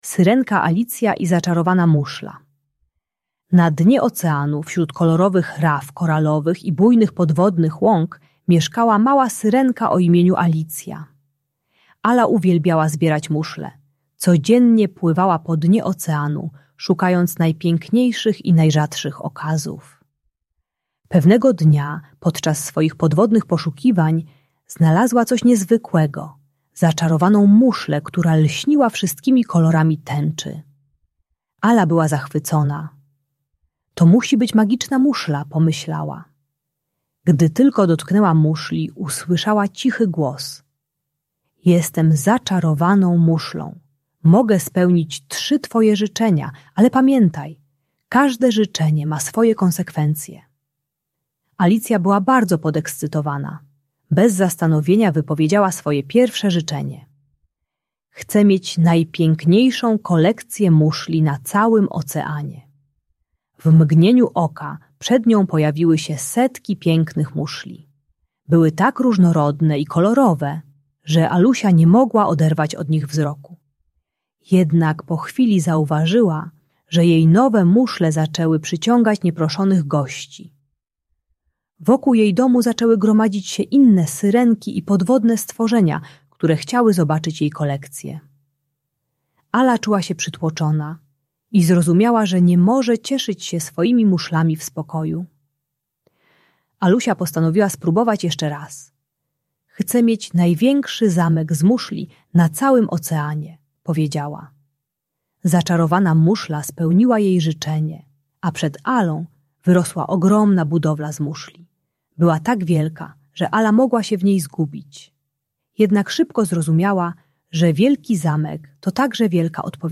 Syrenka Alicja i Zaczarowana Muszla - Bunt i wybuchy złości | Audiobajka